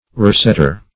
Roughsetter \Rough"set`ter\, n. A mason who builds rough stonework.